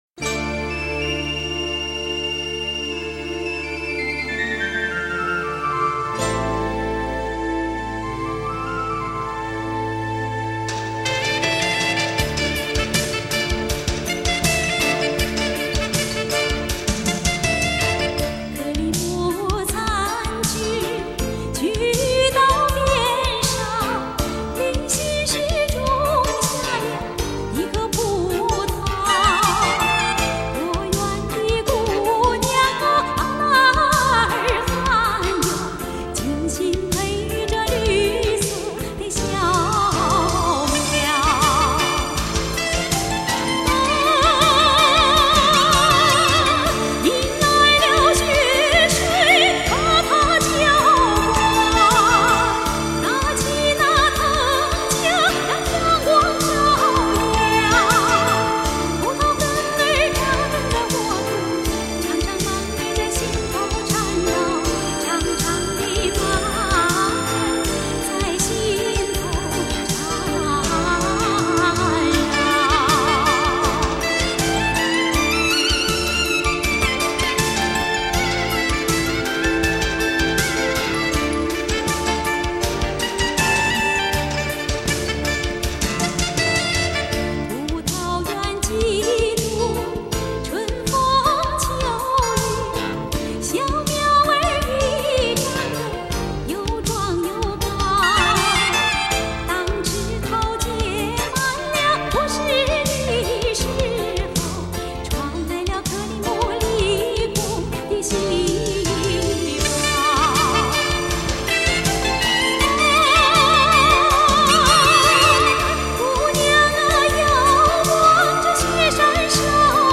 音质：正版CD转320K/MP3